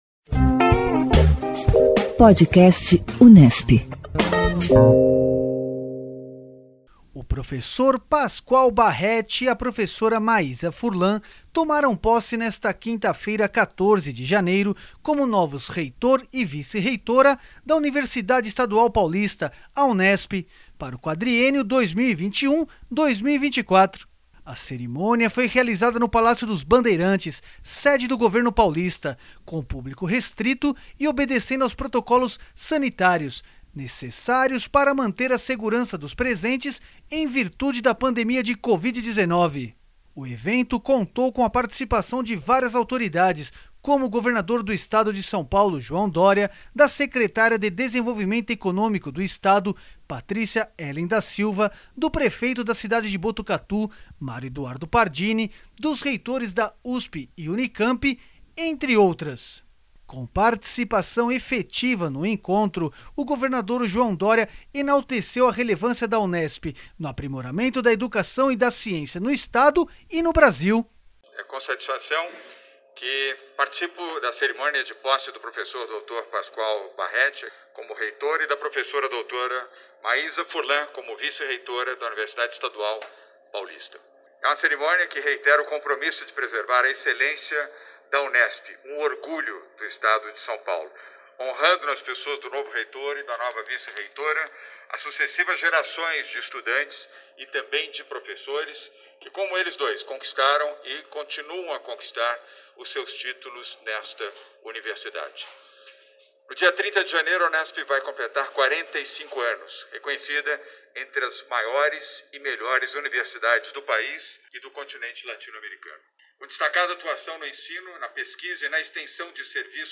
A cerimônia foi realizada no Palácio dos Bandeirantes, sede do governo paulista, com público restrito e obedecendo aos protocolos sanitários necessários para manter a segurança dos presentes, em virtude da pandemia de Covid-19.